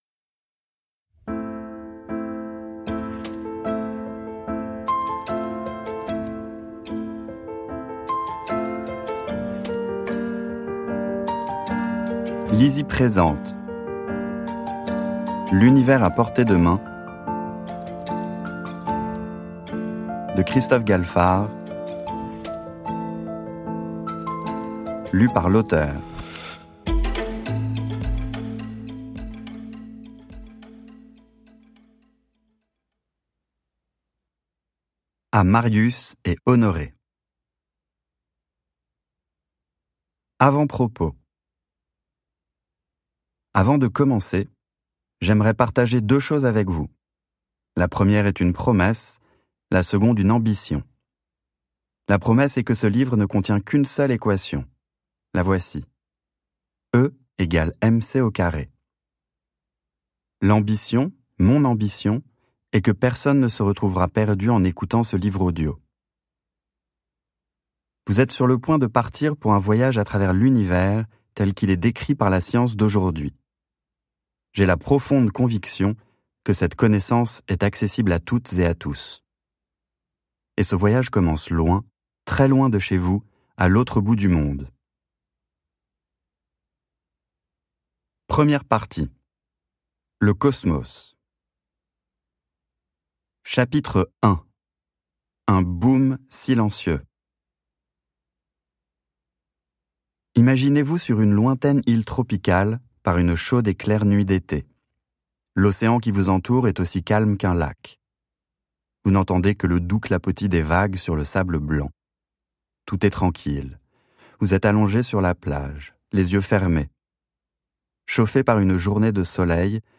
" Imaginez que vous puissiez voyager à travers les étoiles jusqu'aux confins de notre galaxie, plonger au coeur d'un trou noir, entrer dans le monde quantique... Ce livre audio vous offre une ébouriffante odyssée cosmique aux frontières du savoir.